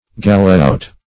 galleot - definition of galleot - synonyms, pronunciation, spelling from Free Dictionary Search Result for " galleot" : The Collaborative International Dictionary of English v.0.48: Galleot \Gal"le*ot\, n. (Naut.)